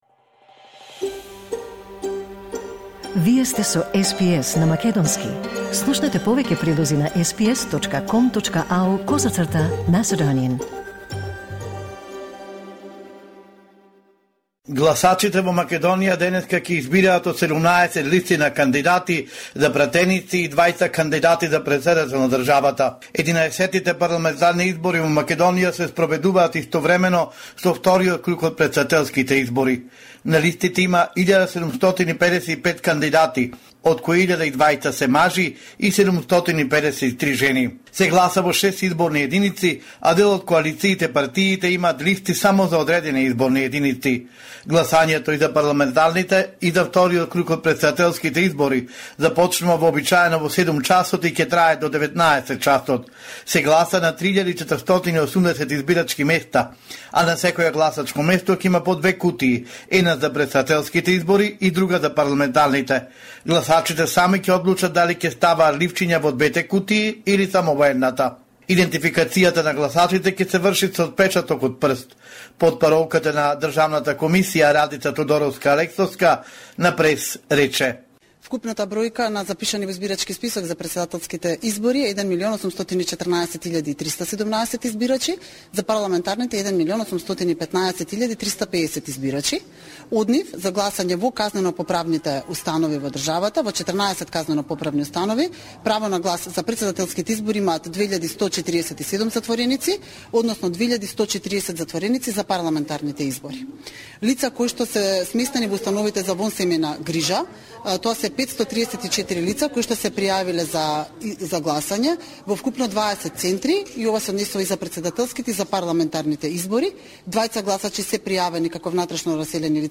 Homeland Report in Macedonian 8 May 2024